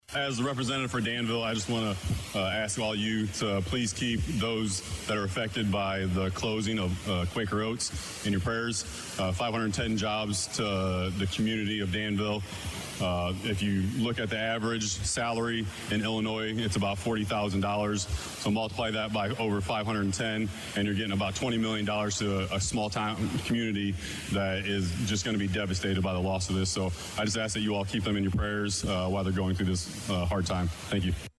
State Rep Brandun Schweizer Offers Comments on IL House Floor about Quaker Oats Closing
With the announcement this week of the closing of the Quaker Oats facility in Danville, State Representative Brandun Schweizer (R-Danville) took to the House floor Thursday to ask his colleagues on both sides of the aisle to keep the families affected by the closing in their prayers.